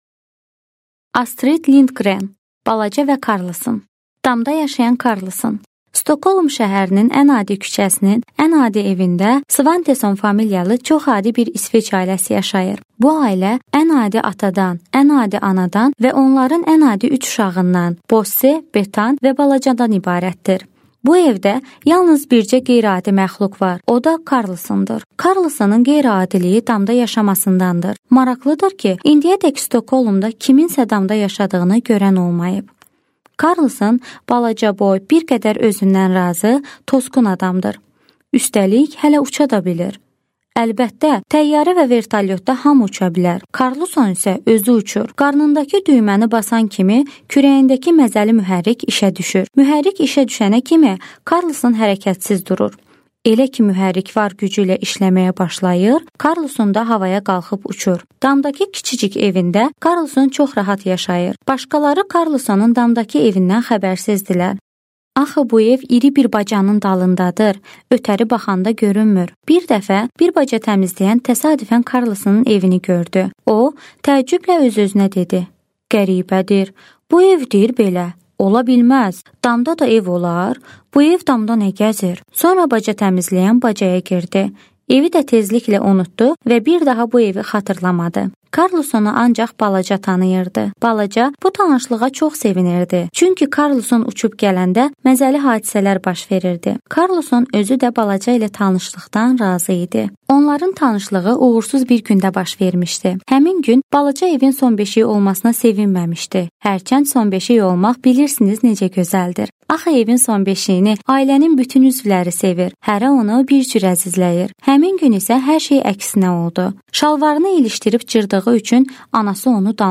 Аудиокнига Balaca və karlson | Библиотека аудиокниг